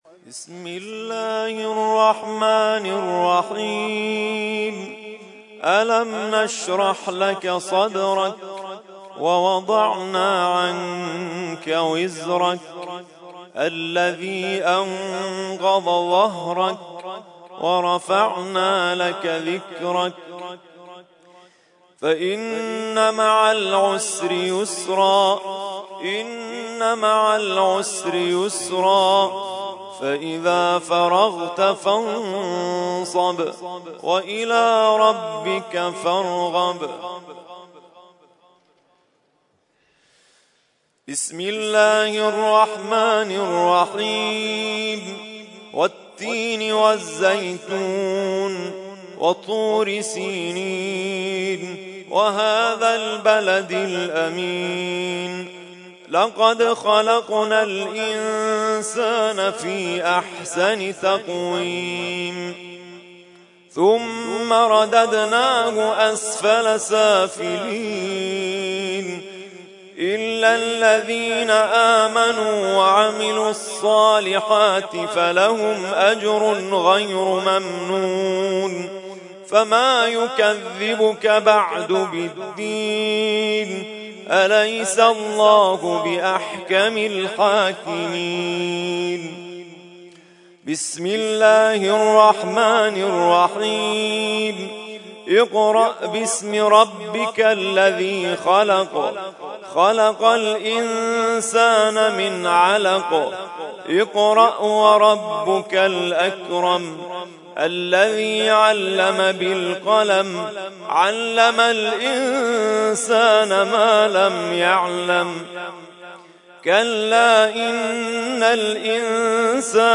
ترتیل خوانی جزء ۳۰ قرآن کریم در سال ۱۳۹۳